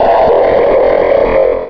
Cri de Kaimorse dans Pokémon Rubis et Saphir.
Cri_0365_RS.ogg